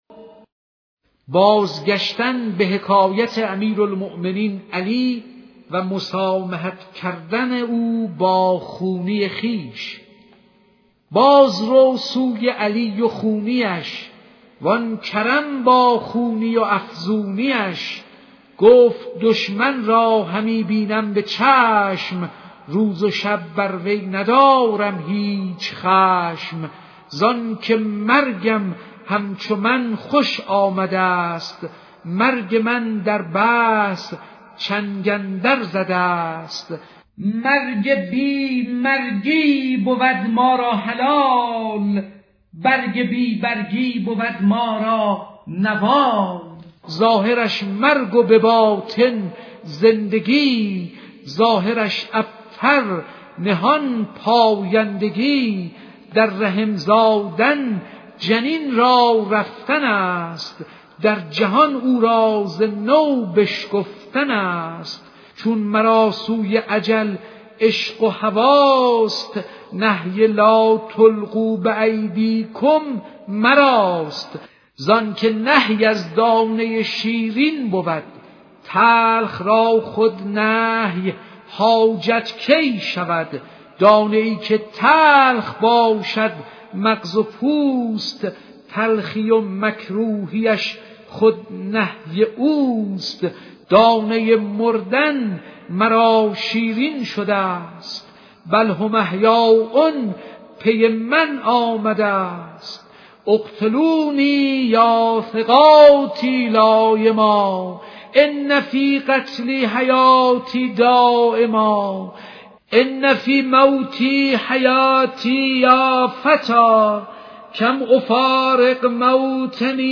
دکلمه بازگشتن به حکایت علی و مسامحه کردن او با خونی خویش